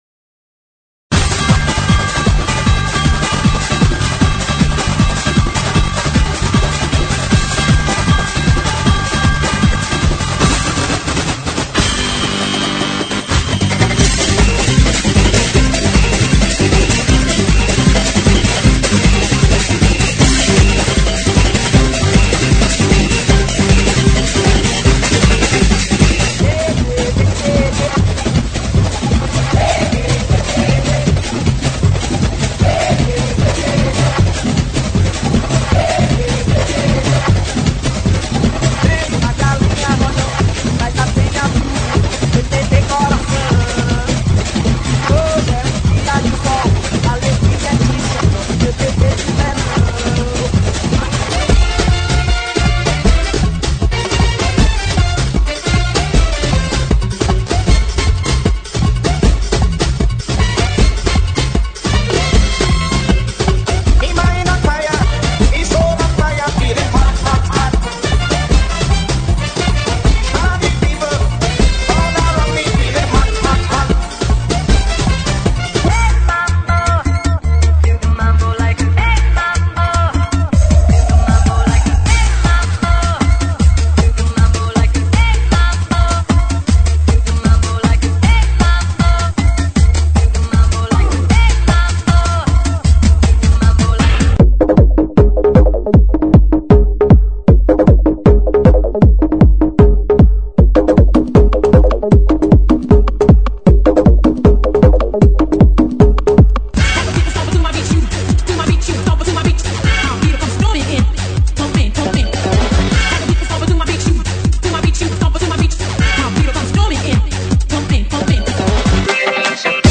GENERO: ELECTRONICA – SAMBA
ELECTRONICA, SAMBA,